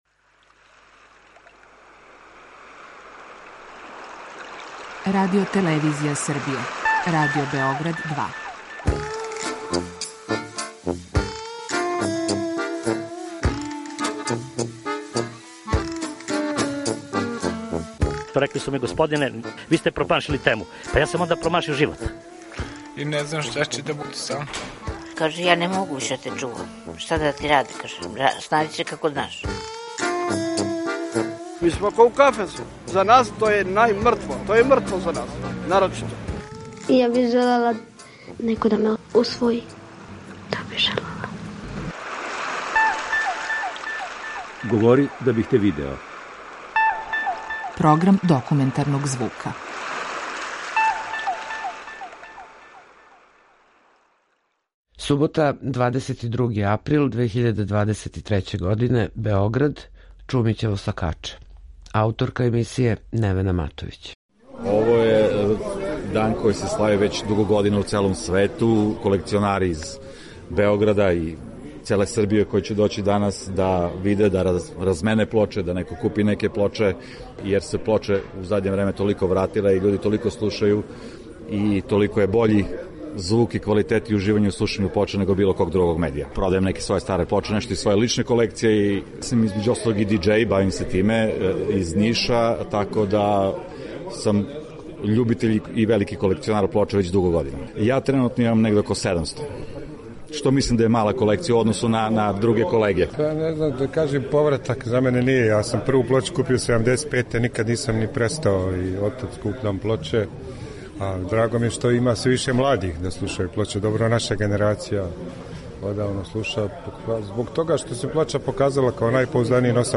Документарни програм
22.april 2023. године, Београд - Чумићево сокаче